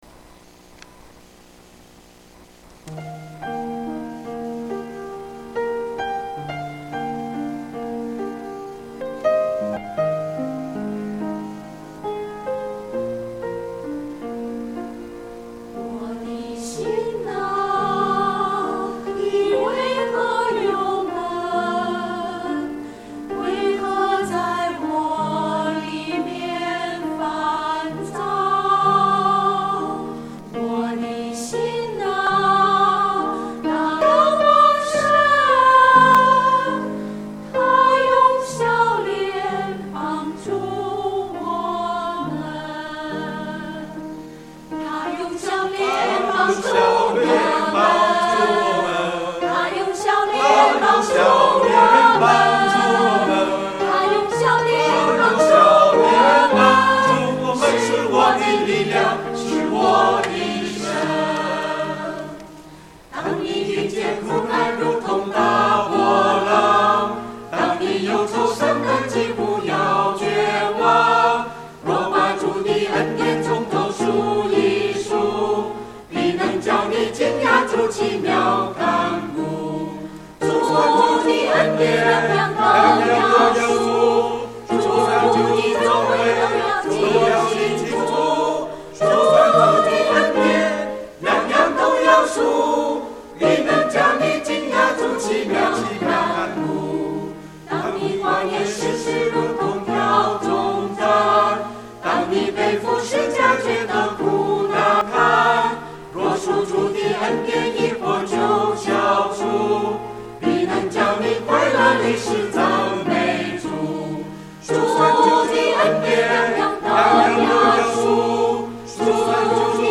• 詩班獻詩